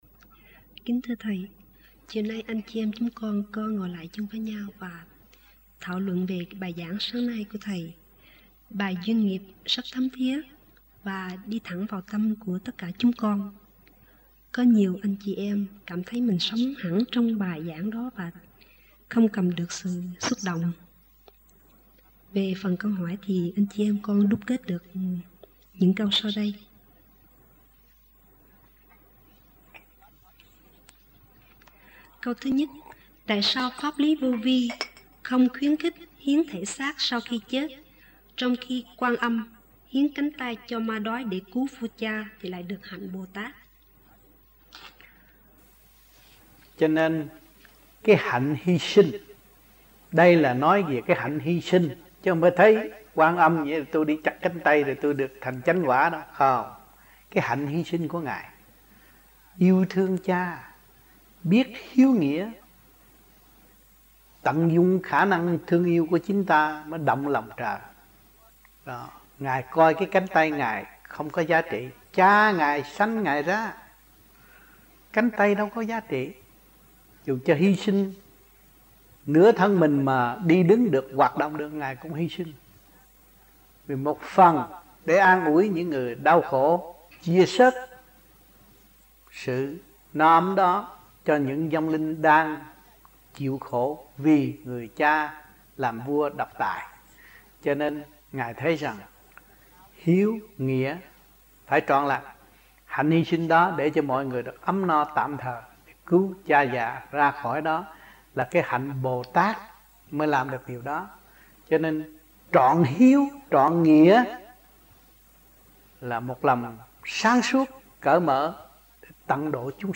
1986-05-15 - VẤN ĐẠO - KHOÁ 4 - THIỀN VIỆN VĨ KIÊN